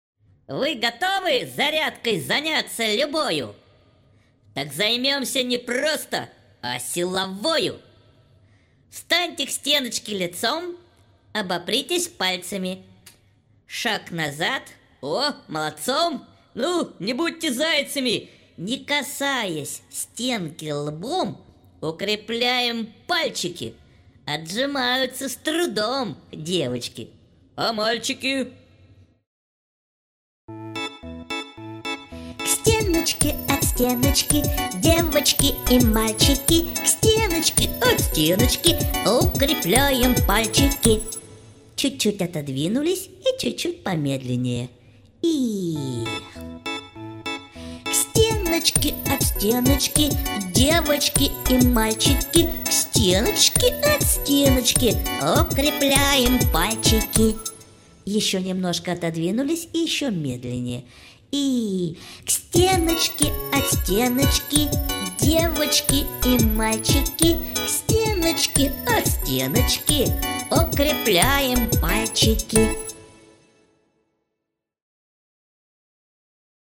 Aудиокнига Музыкальная гимнастика